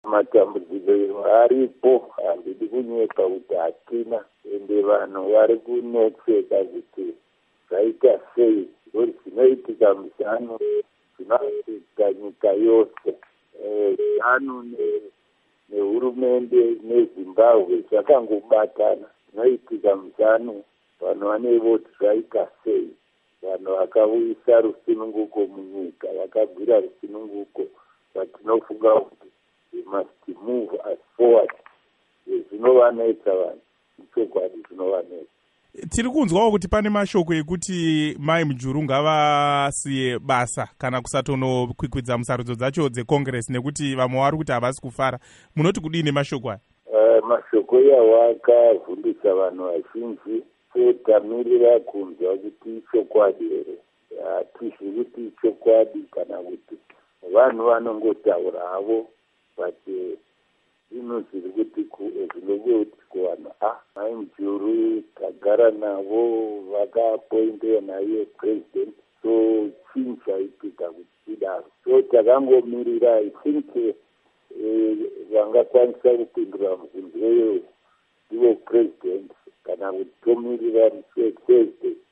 Interview With Cephas Msipa